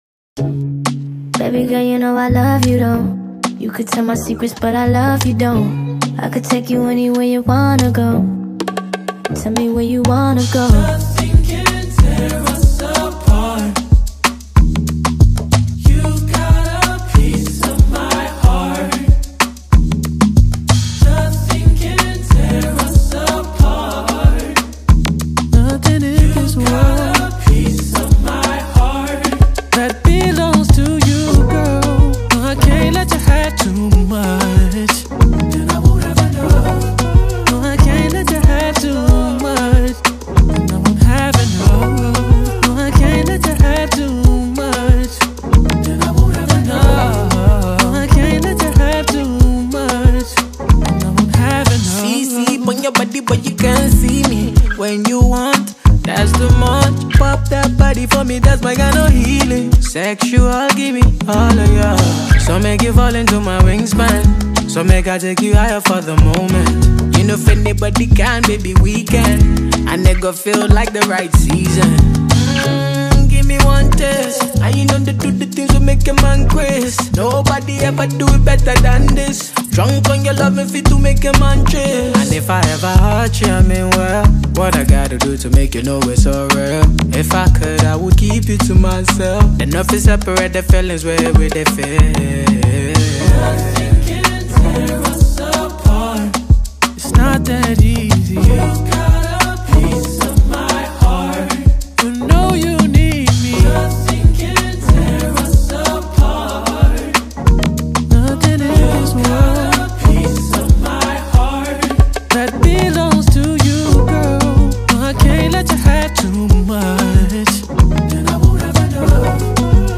With its infectious melody and heartfelt lyrics